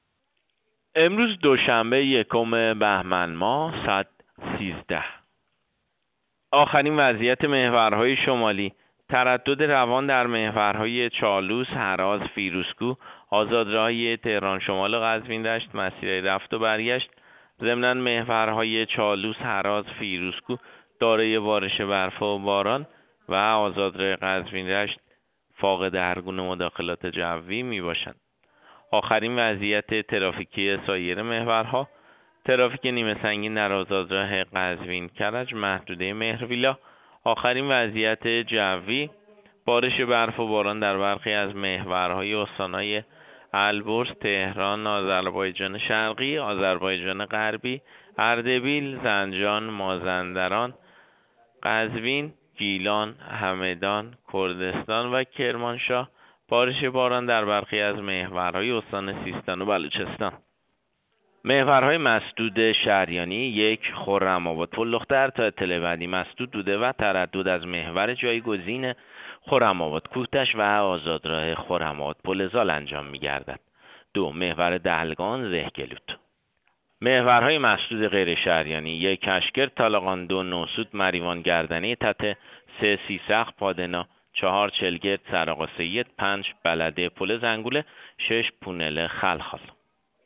گزارش رادیو اینترنتی از آخرین وضعیت ترافیکی جاده‌ها ساعت ۱۳ روز یکم بهمن؛